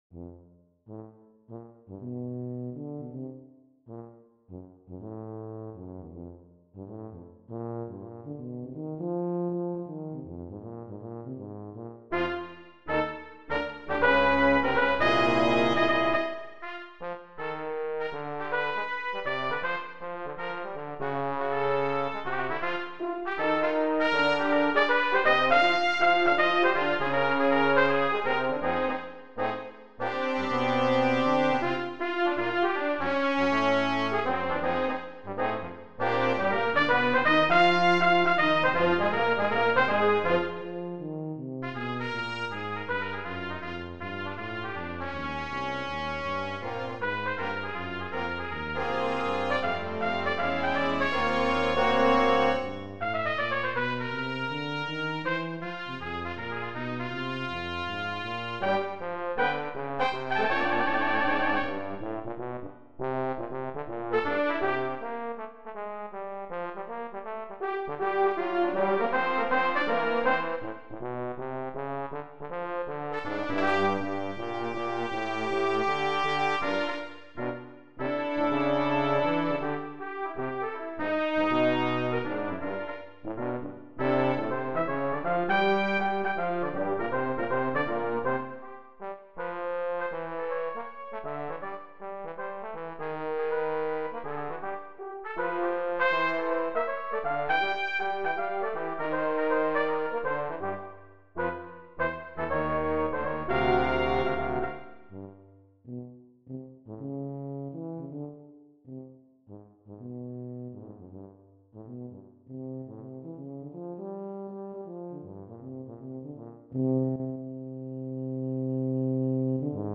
Brass Quintet (optional Percussion)